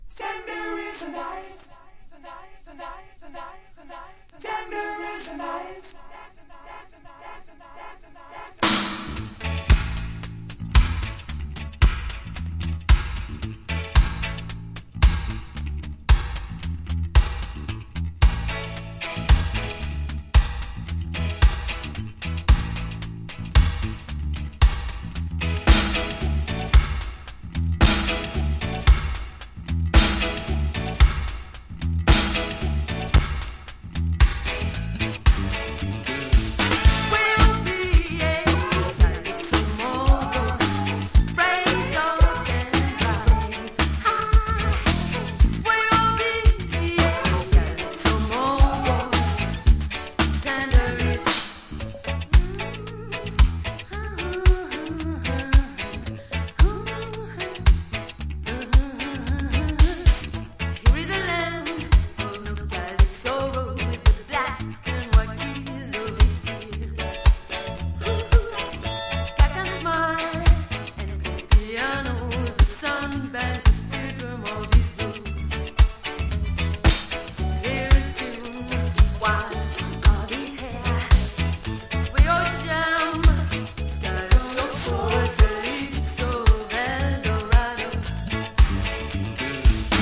DUB / UK DUB / NEW ROOTS